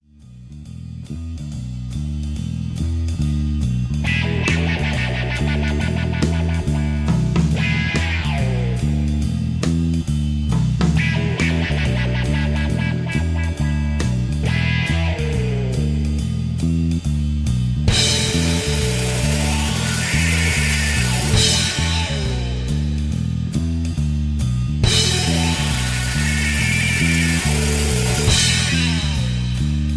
Tags: karaoke , backingtracks , soundtracks , rock and roll